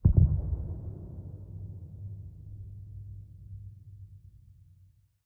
Minecraft Version Minecraft Version 25w18a Latest Release | Latest Snapshot 25w18a / assets / minecraft / sounds / mob / warden / nearby_close_1.ogg Compare With Compare With Latest Release | Latest Snapshot
nearby_close_1.ogg